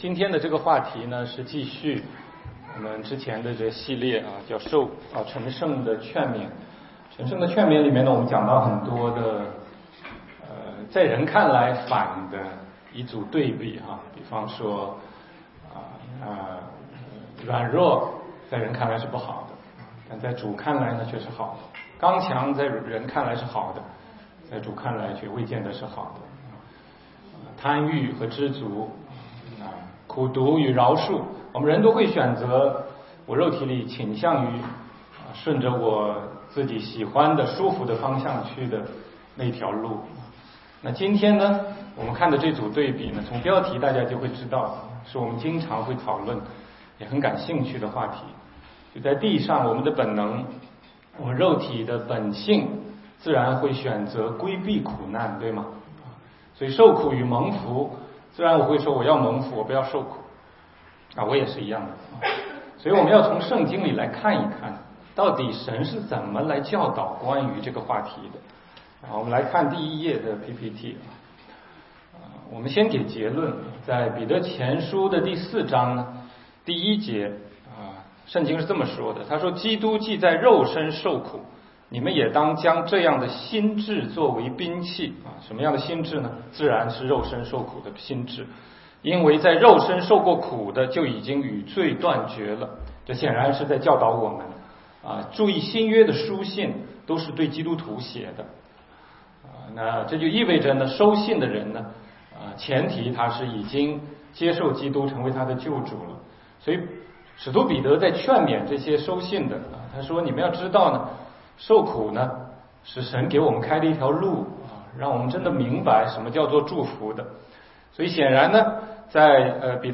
16街讲道录音 - 成圣的劝勉系列之六：受苦与蒙福